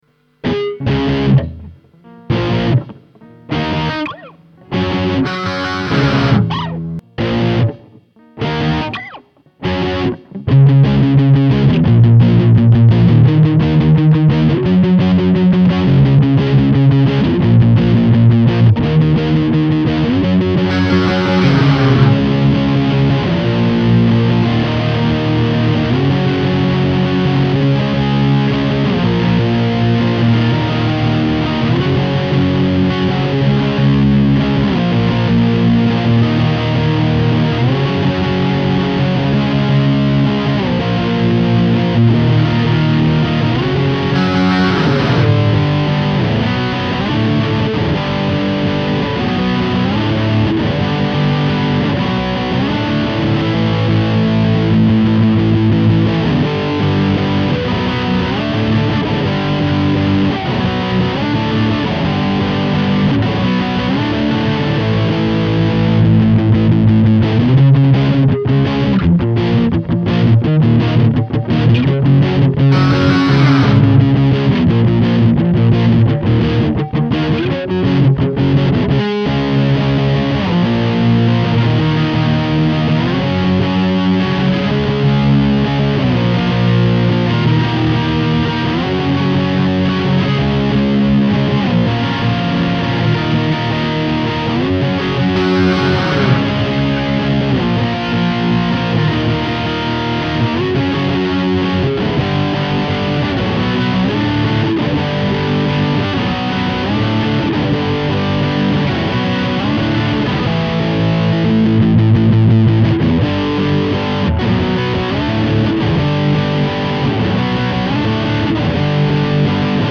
Again I struggled with the drums and, after a week, decided to ditch them all together... for now... all of my tracks are works in progress and will be revisited and tweaked. I was worried that there is not much to this song (no solo's, basically only one guitar track), but after listening to a lot of my favourite songs I realised that not all of them are hard to play - apart from one or two of the solo's, I can play them all... maybe that's just an excuse though 'cos I am not good enough to come up with major riffs and solos!